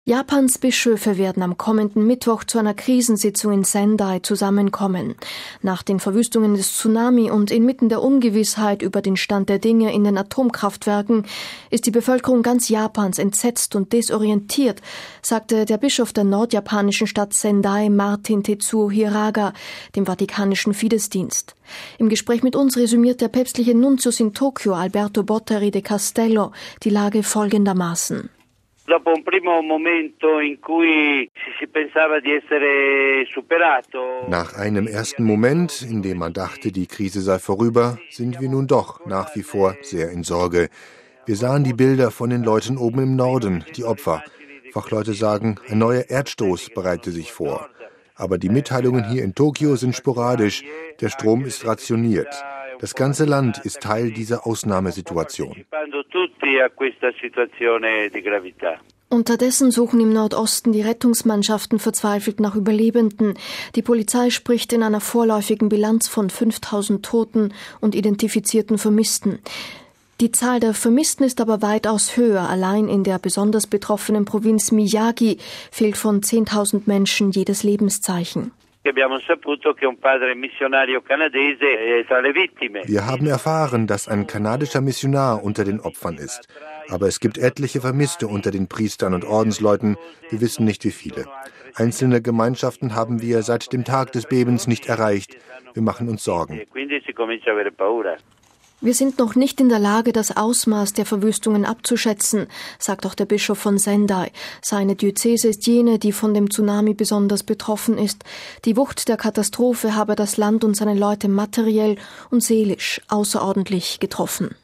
Im Gespräch mit uns resümiert der päpstliche Nuntius in Tokio, Alberto Bottari de Castello, die Lage folgendermaßen: